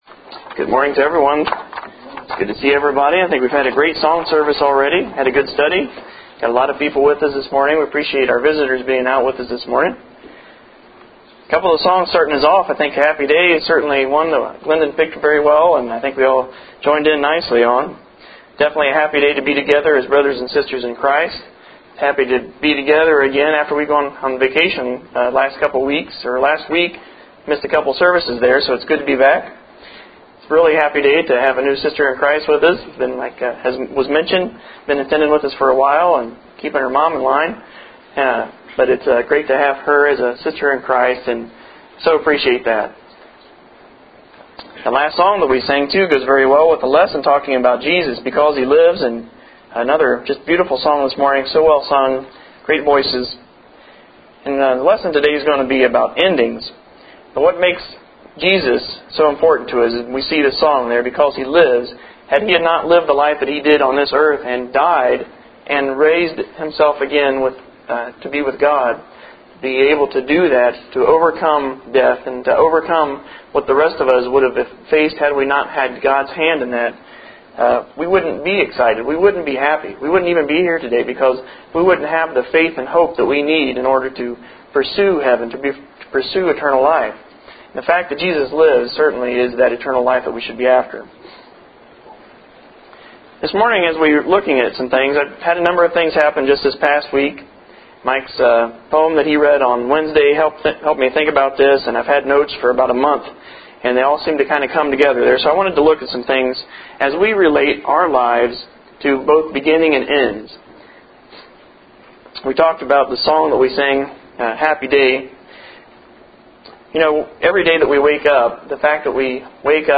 Endings Lesson – 04/17/11